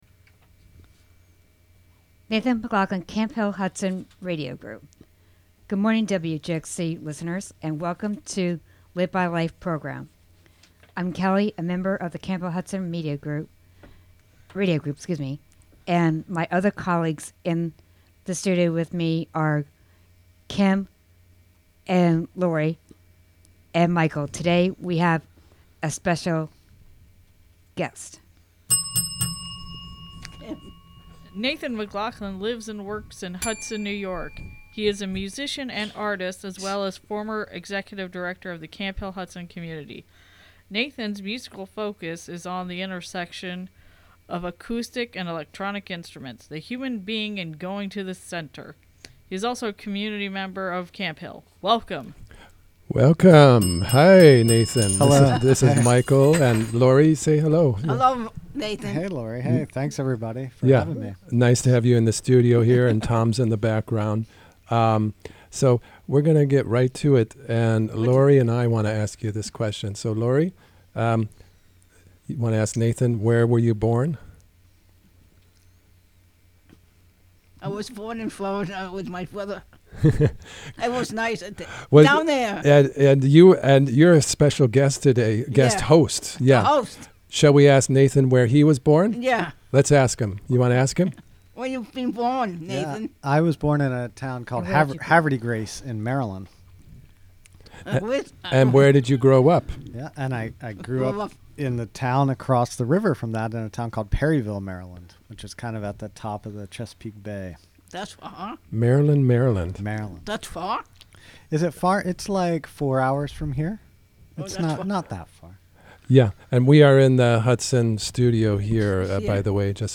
(Audio) Feb 29, 2024 shows Live By Life Produced by the Camphill Hudson Radio Group. Interview by the Camphill Media Group.